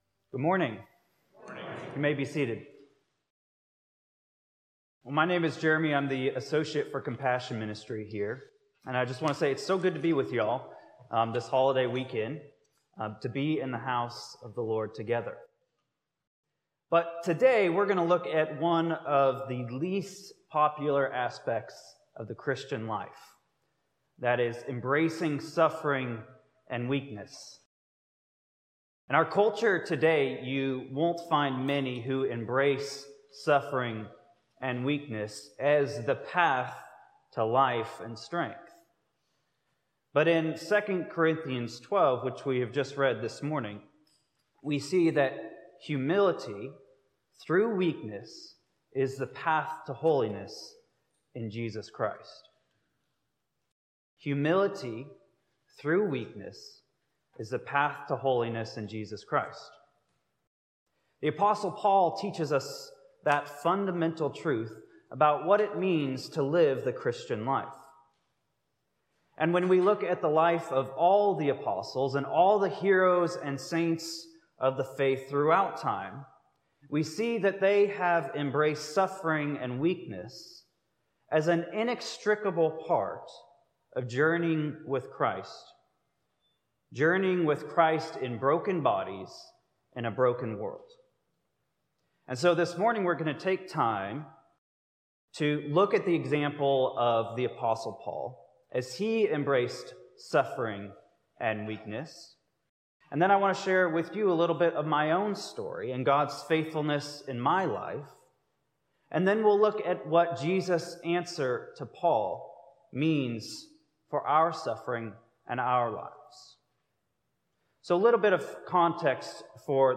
Sermons - Holy Cross Anglican Cathedral